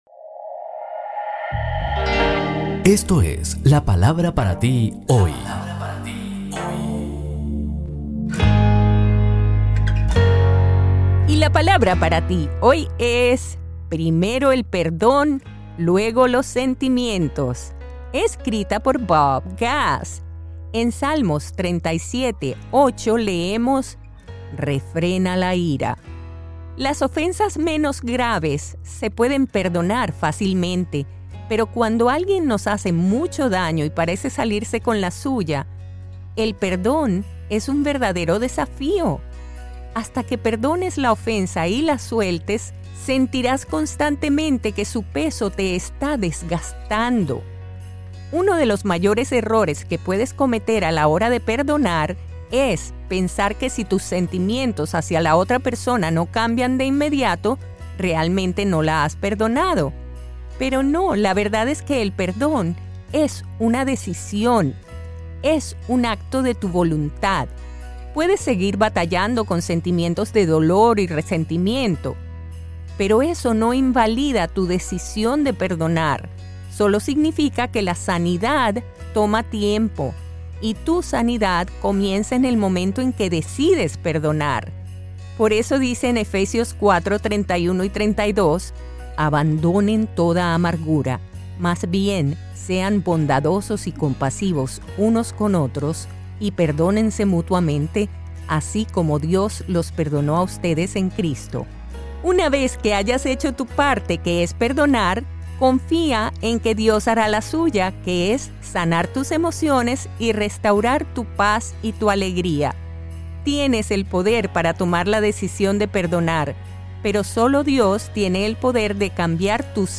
Un episodio súper interesante, escrito por Bob Gass y narrado por la dulce voz de Elluz Peraza.